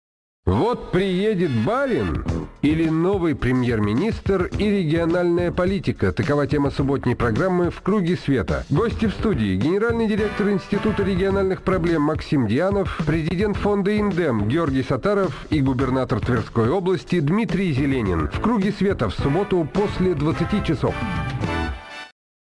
на радио «Эхо Москвы»
Аудио: анонс –